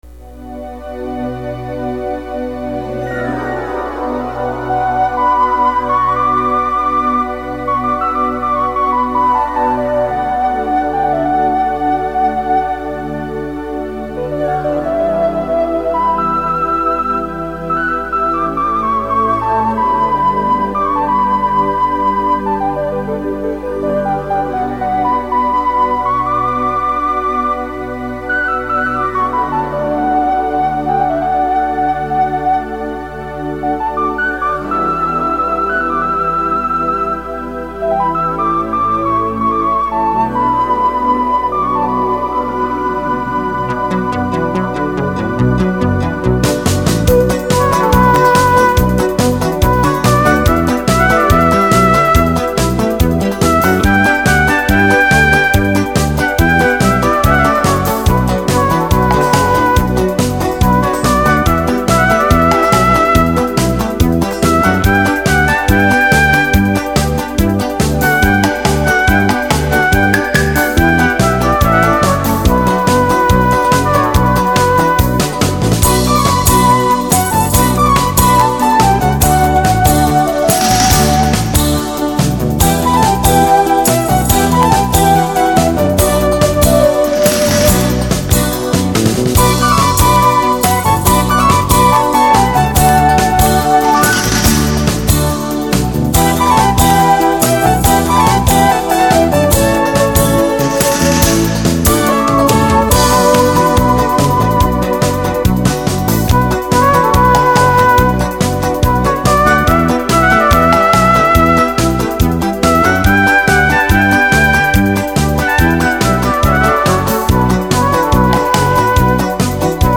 风格为南美印第安旋律
而此类音乐的特点就是激荡，流畅，起伏跌宕，鼓，笛，排箫运用得很多！灵巧而反复音符！
反复的节奏不是很多，音场比较狭窄而充实，灵性被整体结构的错落层次所笼罩。